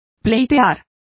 Complete with pronunciation of the translation of litigated.